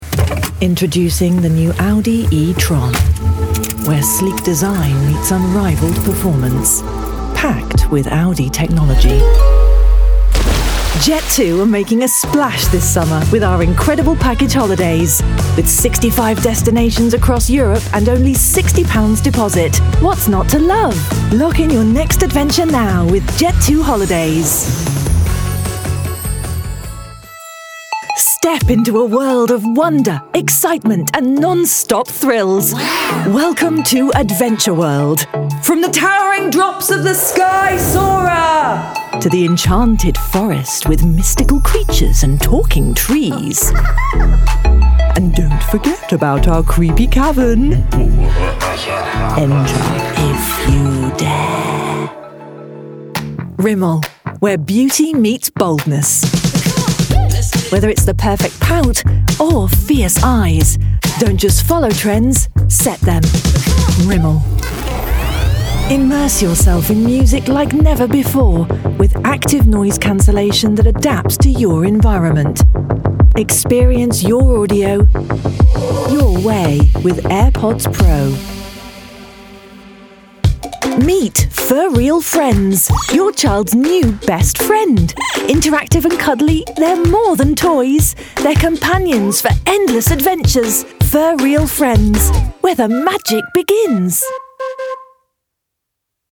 There's a directness and clarity in her delivery that instantly elevates any script — whether it's corporate, commercial, or narrative.
Commercial-Reel-2-.mp3